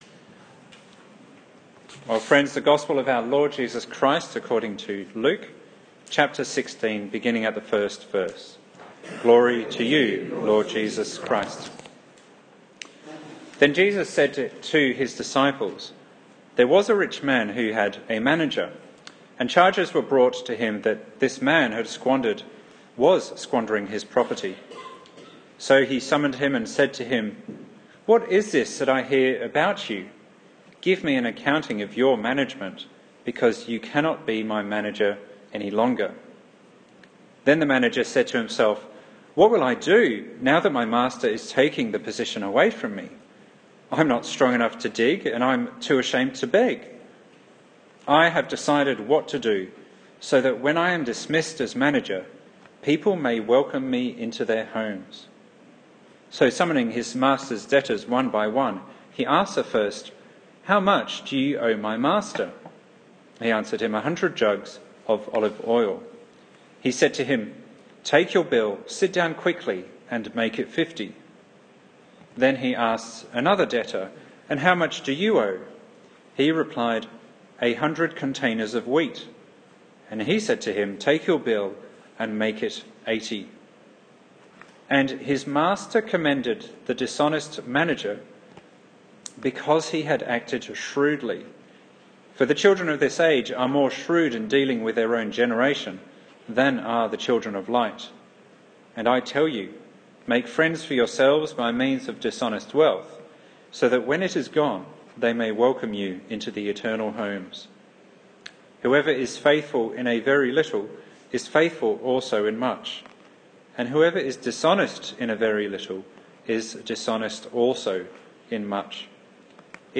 2016 Called to Swift & Deliberate Action Preacher